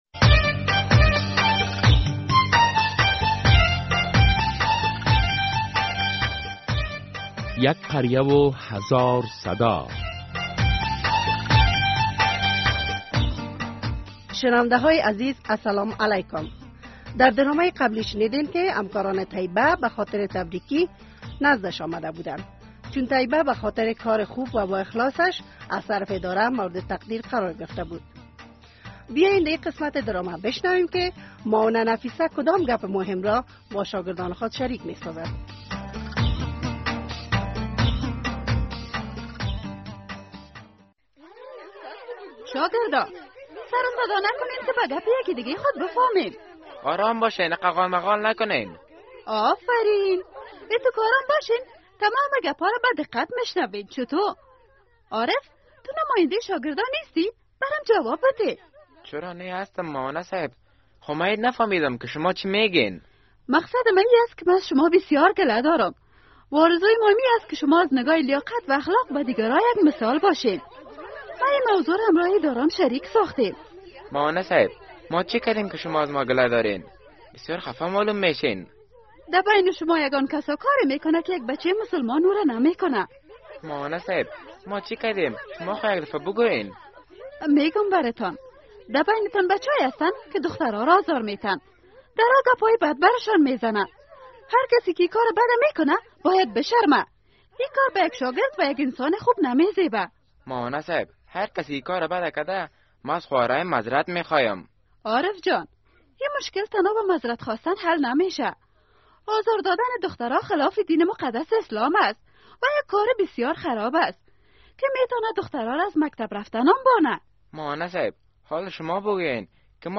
دوصد و سومین قسمت درامه یک قریه هزار صدا